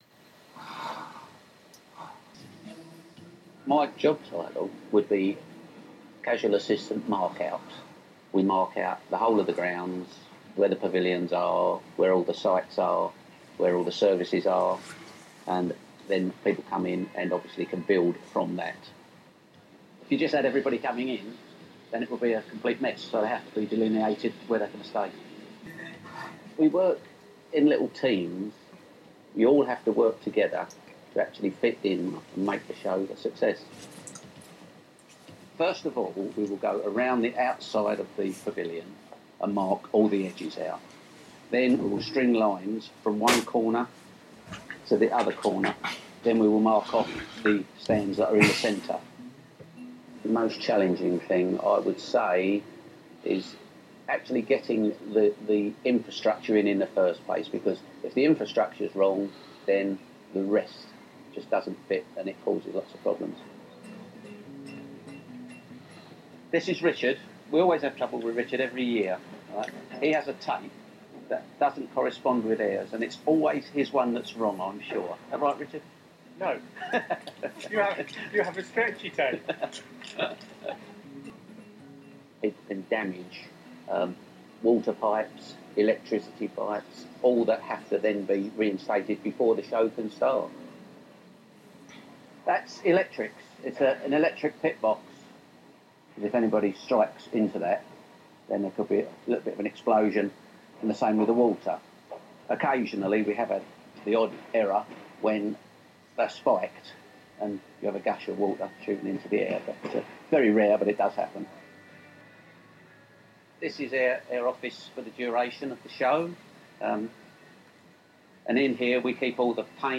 This speech has a Popular London basis which is modified in the direction of GB and can be considered nowadays to be London Regional GB.
He pronounces cup of tea carefully, and also again as /əɡeɪn/ rather than /əɡen/.
In consonants the most obvious variation concerns the use of glottal stop for /t/, particularly intervocalically.
Dark [ɫ] is consistently vocalised; this is acceptable not only in London-Regional GB but in GB more generally people, build, also, little.
The spelling <-ing> is regularly /ɪn/ rather than /ɪŋ/ getting, marking.
Vowels vary between broad London and near GB:
/ɔː/ is regularly [ɔʊ] all, corner, causes, also, transformed, unfortunately
In fact /aʊ/ and /əʊ/ are very close to one another.